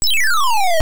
Game Sounds:
Fall into pit
Fall-into-Pit.wav